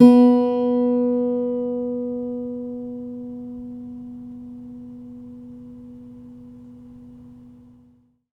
R - Foley 7.wav